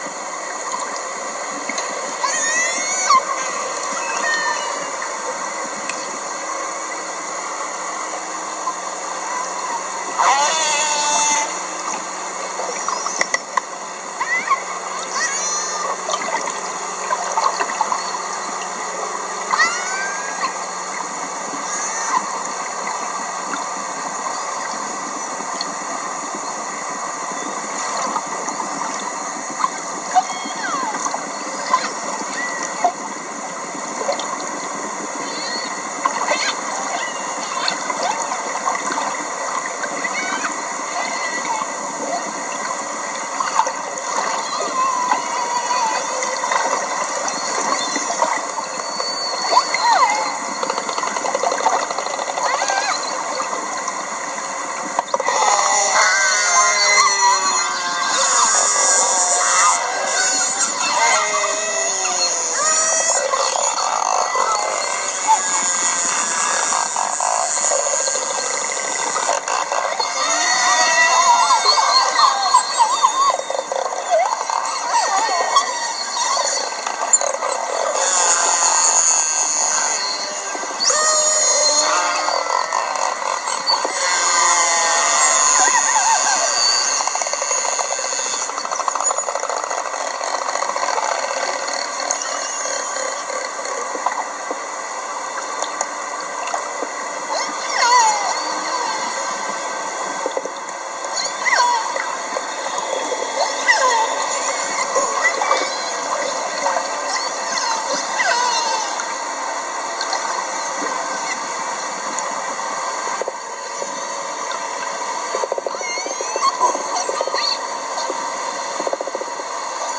Transient Orca Party T18/T19s, T46s, T65As, T36s? – Vocalizing